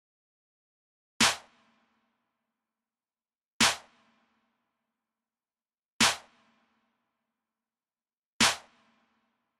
进化 拍手
标签： 100 bpm Hip Hop Loops Drum Loops 1.62 MB wav Key : Unknown
声道立体声